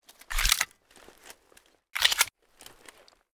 92fs_magcheck.ogg